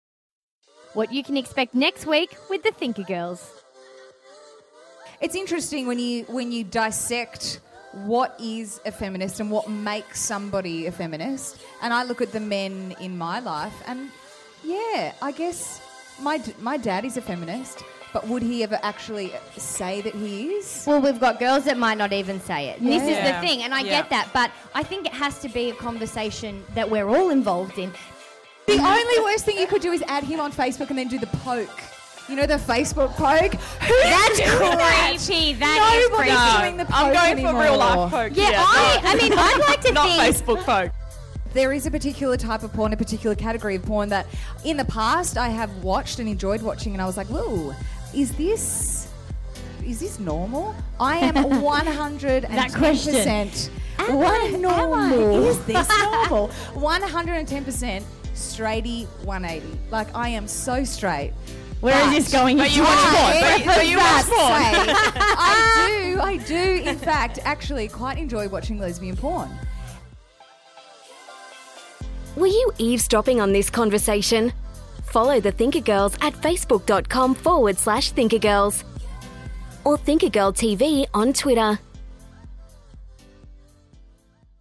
LIVE from the Adelaide Fringe!